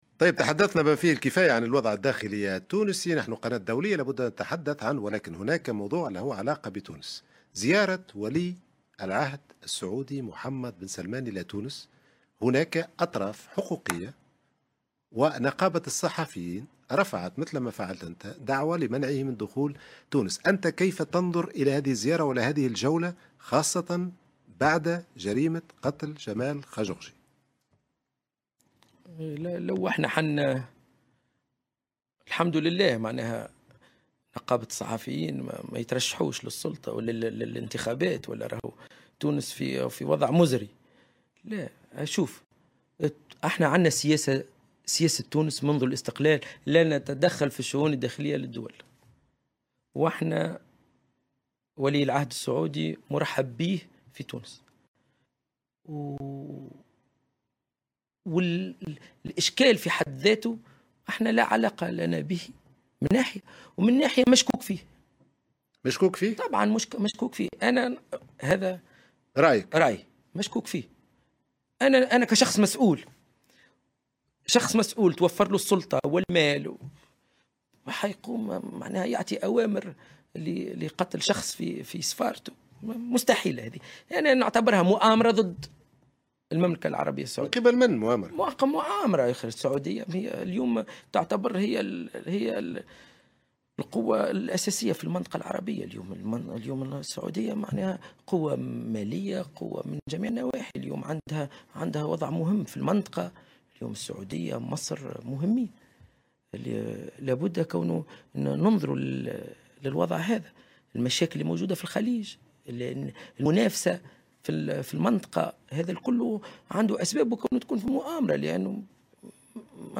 واعتبر الرياحي، في حوار مع قناة "فرانس 24"، اليوم الجمعة، أن سياسة تونس التي تعتمدها منذ الاستقلال والتي تقوم على على عدم التدخل في الشؤون الداخلية للدول، وهو ما يجعل ولي العهد السعودي مرحبا به في تونس، مشيرا في الآن ذاته إلى أن تورط بن سلمان في جريمة اغتيال الصحفي السعودي جمال خاشقجي "مشكوك فيه"، حسب رأيه.